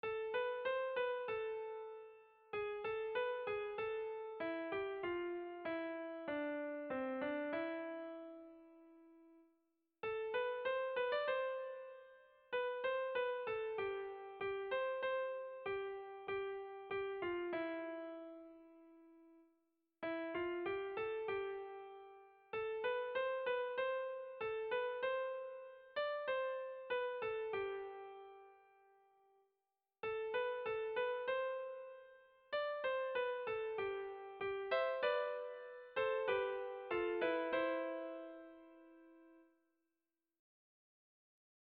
Sentimenduzkoa
Zortziko handia (hg) / Lau puntuko handia (ip)
ABDE